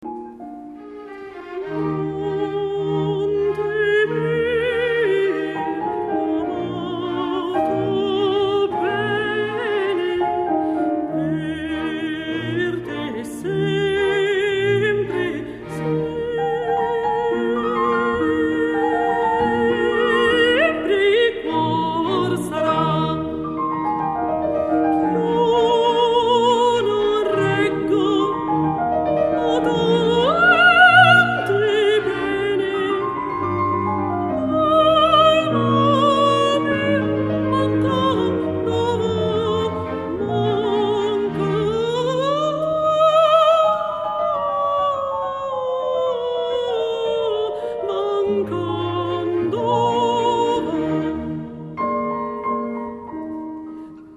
Mozart - aria da concerto - ch'io mi scordi di te?